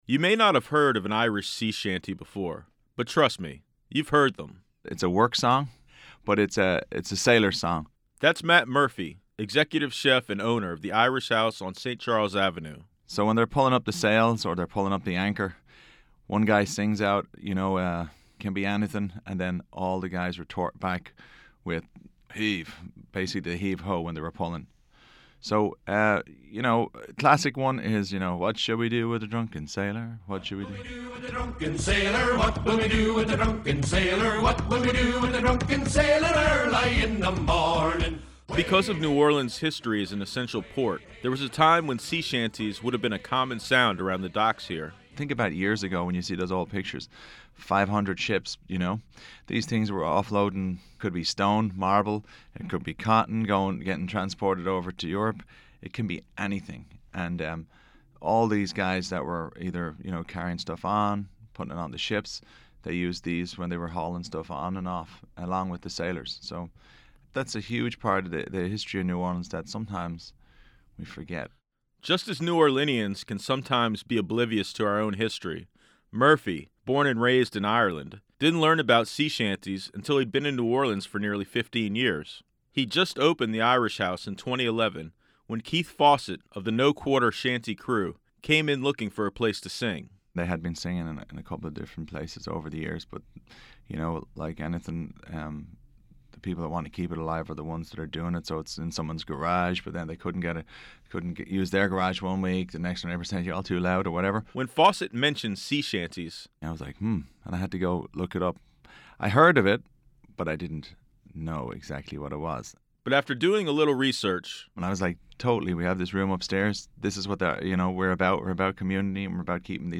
Audience participation is not only allowed, it’s encouraged.